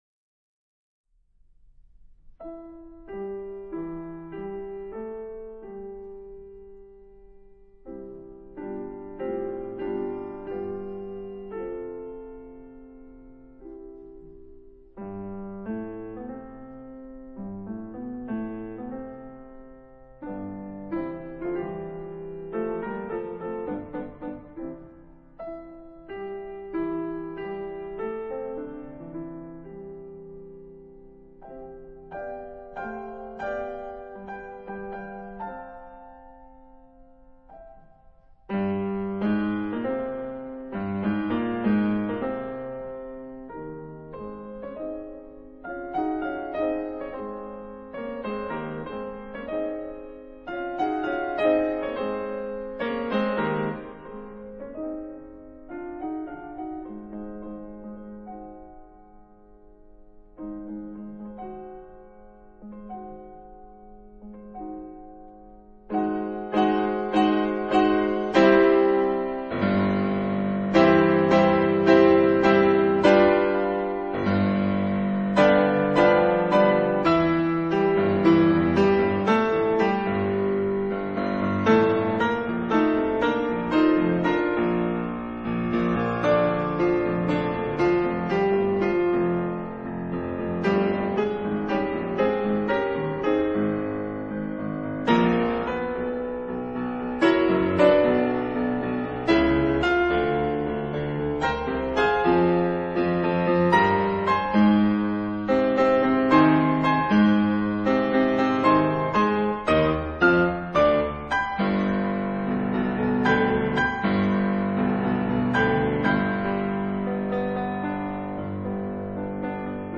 他小心翼翼地處理每顆進來的音符，
透過指尖力道與音色控制，讓他們像是慢速的黑白默片般，